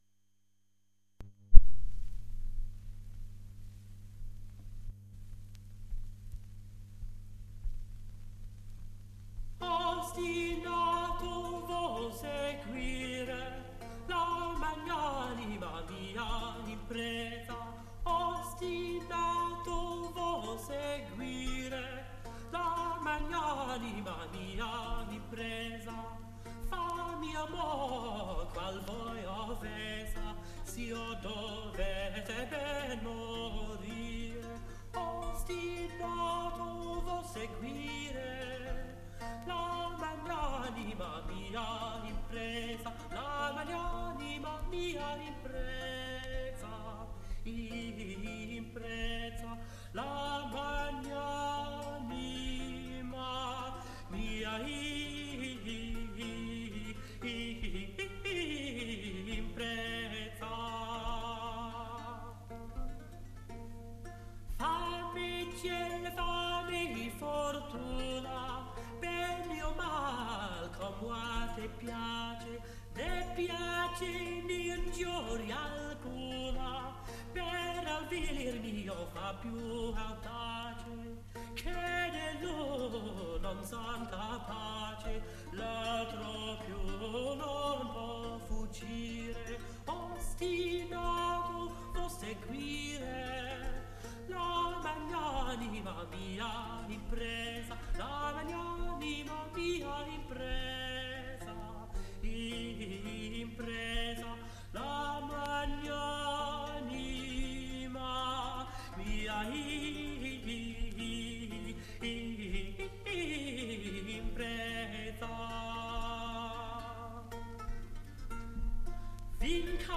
Tromboncino, Ostinato vo' seguire (frottola).mp3